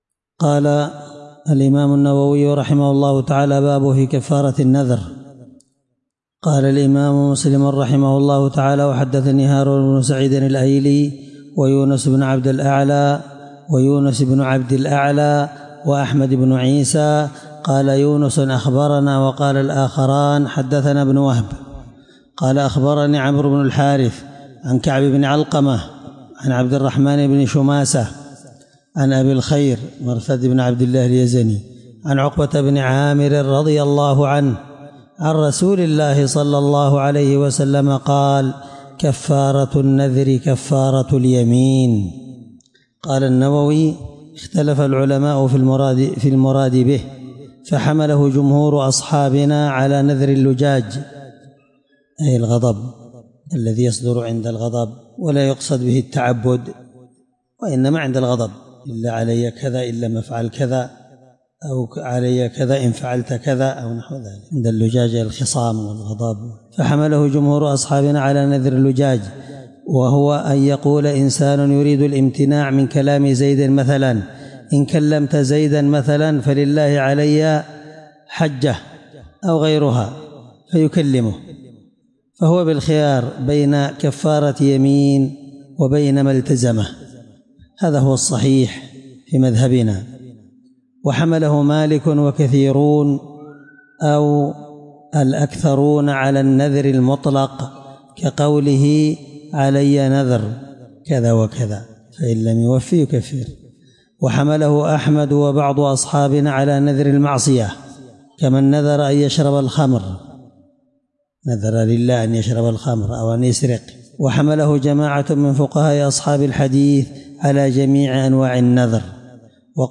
الدرس5من شرح كتاب النذر حديث رقم(1645) من صحيح مسلم